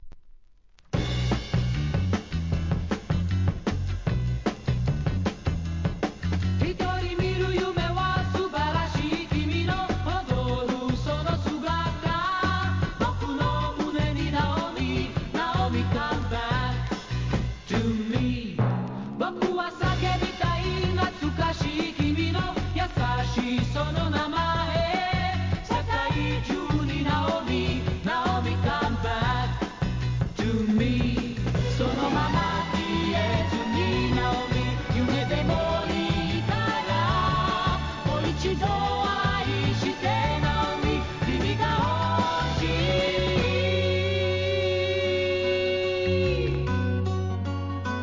¥ 220 税込 関連カテゴリ SOUL/FUNK/etc...
イスラエルのデュオグループ、